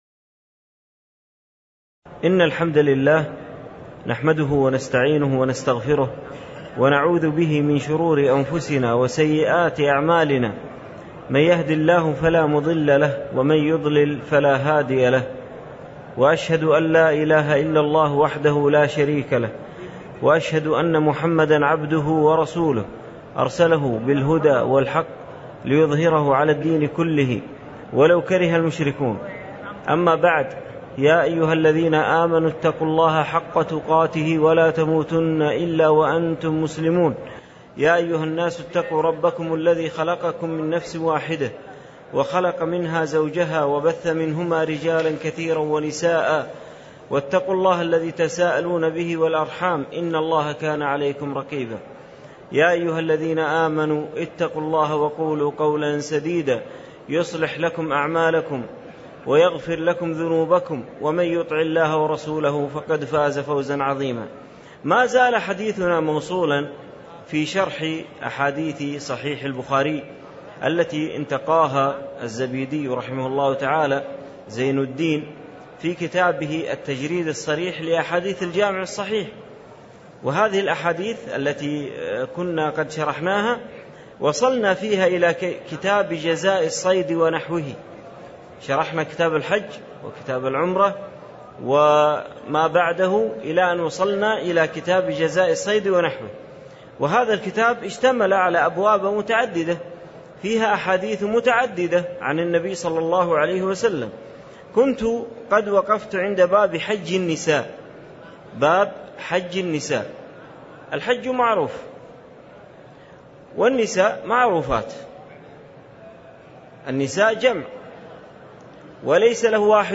تاريخ النشر ٤ ذو الحجة ١٤٣٧ هـ المكان: المسجد النبوي الشيخ